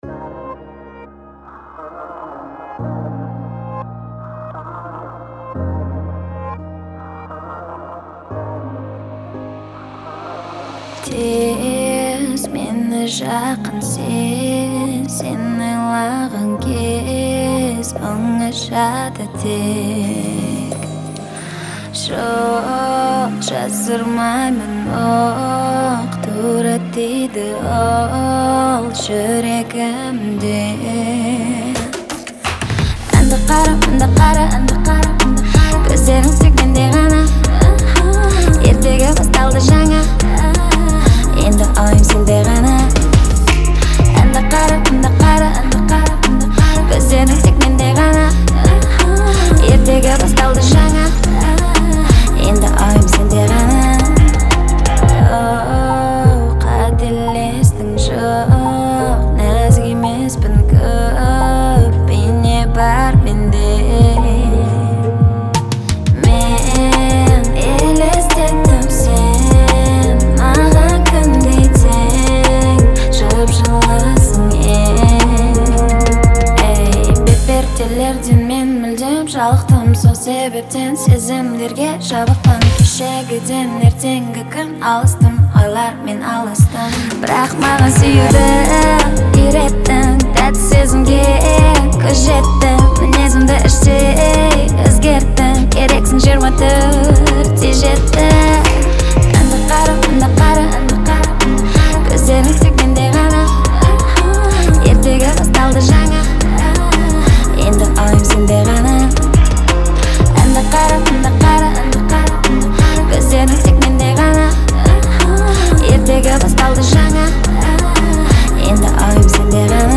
энергичная поп-песня
которая сочетает в себе элементы R&B и электронной музыки.